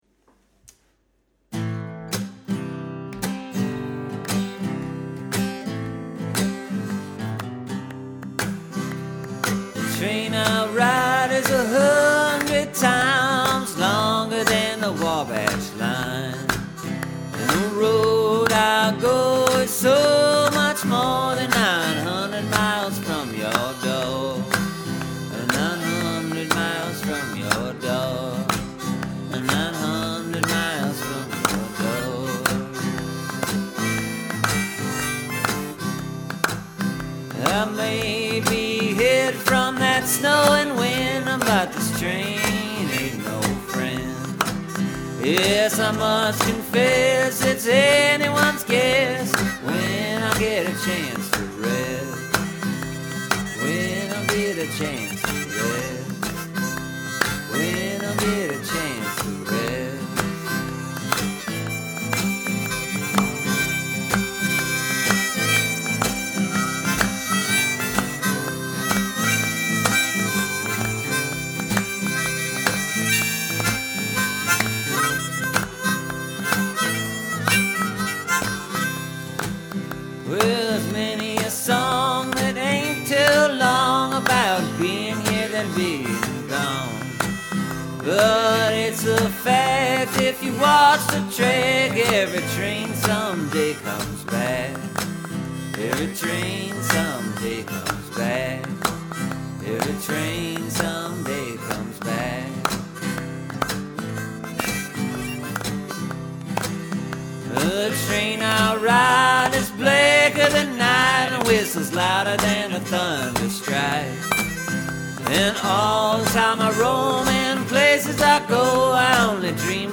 Clapping is hard work.
I just haven’t played mouth harp for, oh, about 4 months and it’s not a good thing to jump back into.
It’s a simple tune.